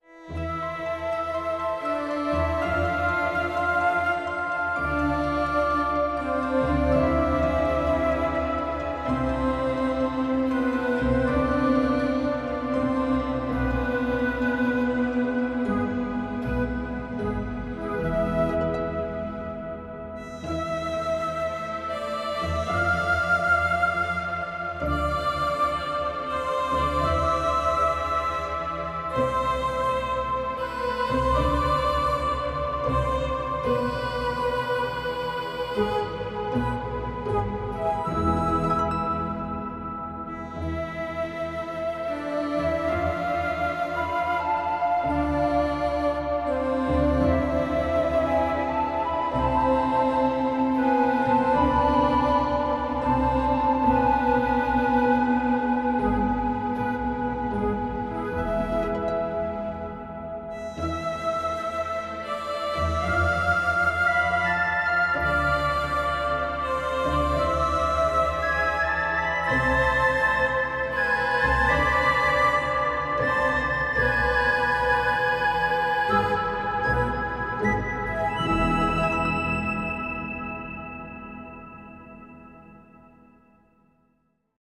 varied, some rhythm, nice melodies and nature-sounds